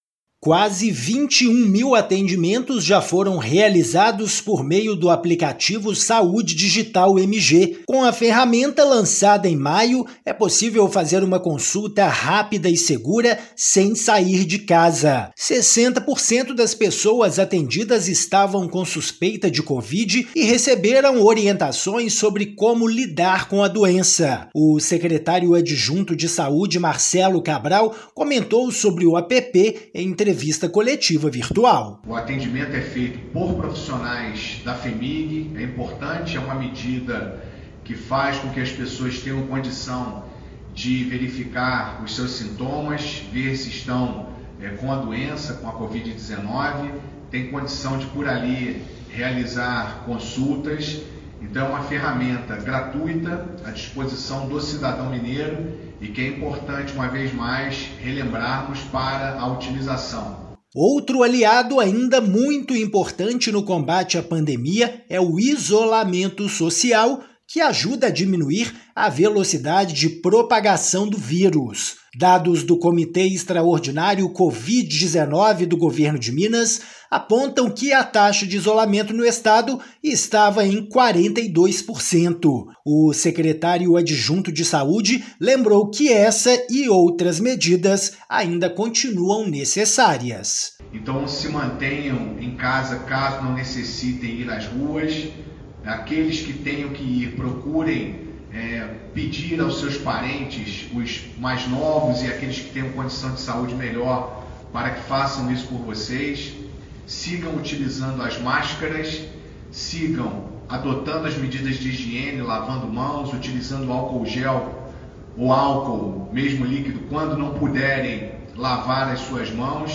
Um levantamento mostra que 60% das pessoas atendidas estavam com suspeita de covid-19 e já receberam orientações sobre como lidar com a doença. Ouça a matéria de rádio.